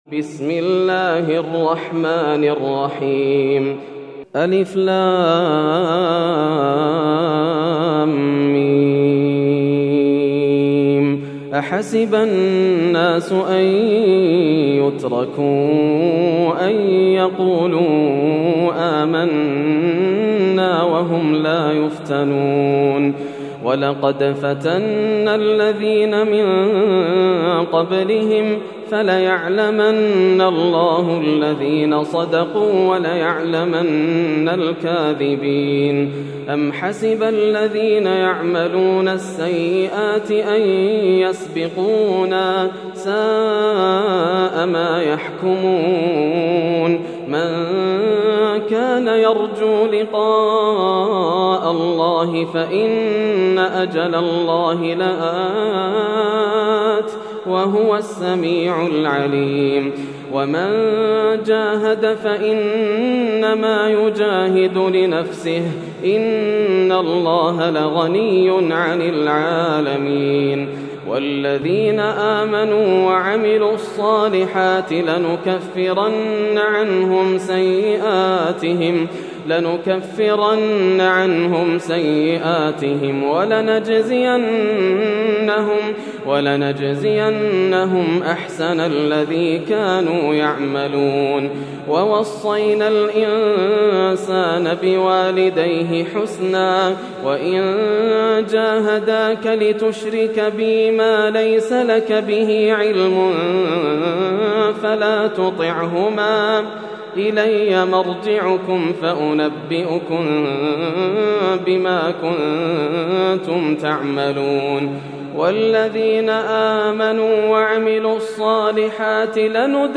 سورة العنكبوت > السور المكتملة > رمضان 1431هـ > التراويح - تلاوات ياسر الدوسري